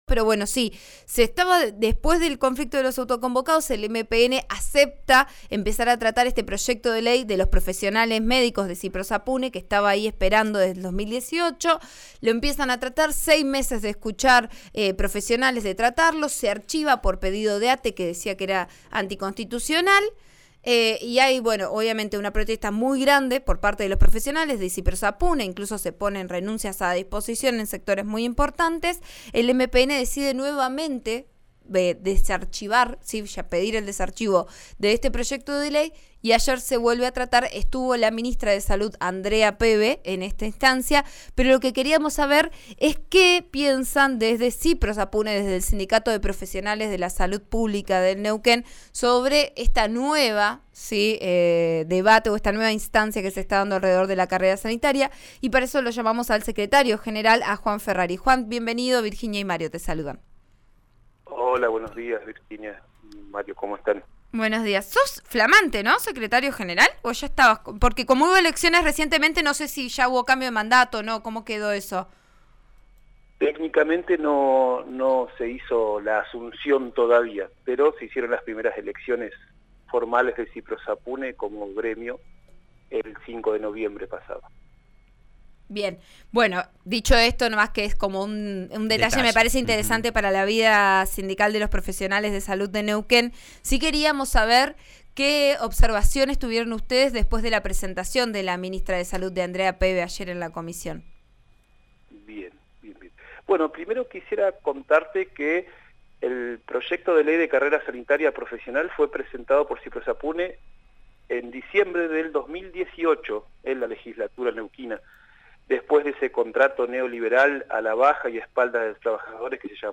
En declaraciones a Vos A Diario, el programa de RN Radio 89.3, el dirigente criticó que la funcionaria haya sugerido invitar a las conducciones del sistema, incluidos los titulares de clínicas privadas, y que no haya sido clara en lo que aún queda por discutir del articulado.